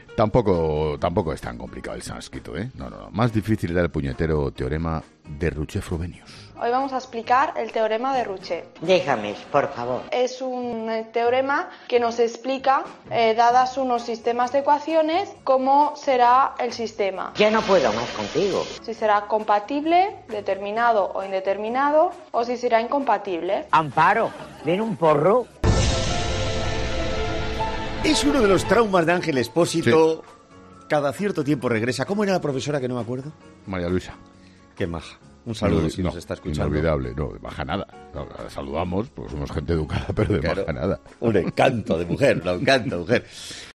un audio de una joven tratando de explicar precisamente ese concepto